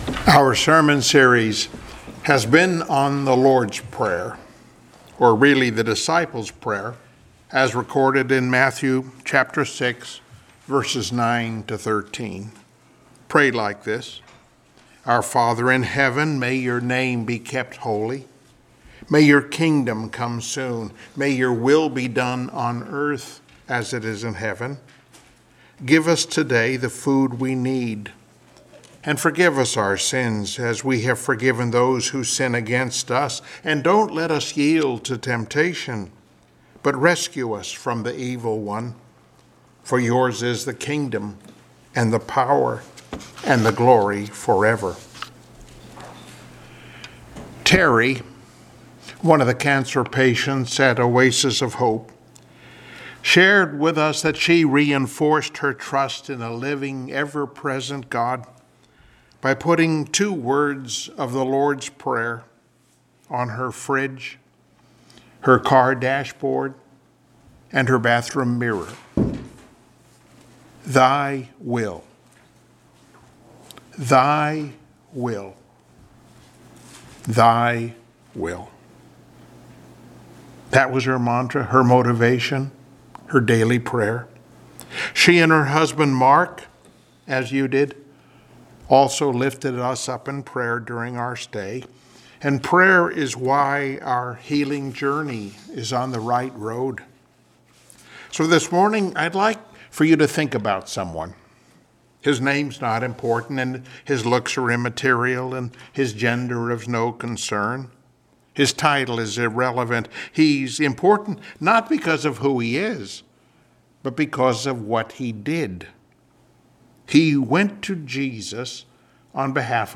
Passage: Revelation 8:1-5 Service Type: Sunday Morning Worship